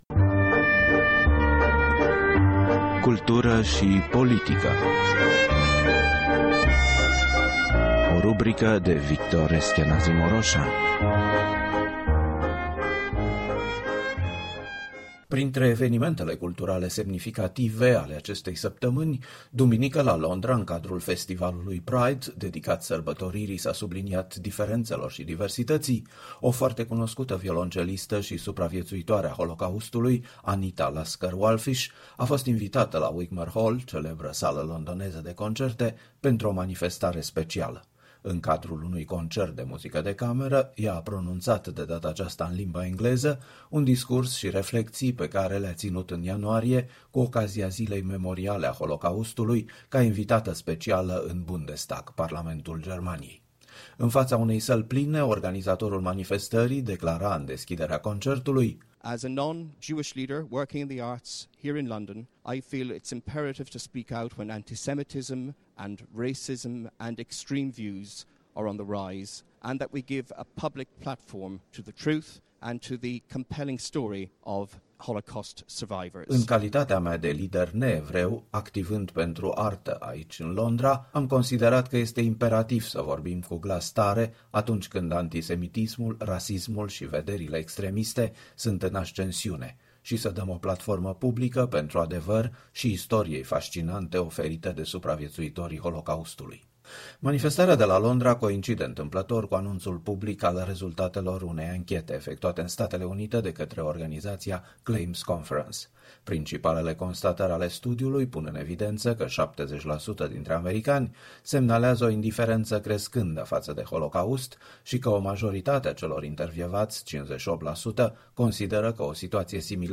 Violoncelista, supraviețuitoare a Holocaustului, Anita Lasker-Wallfisch, depune mărturie la Wigmore Hall, la Londra.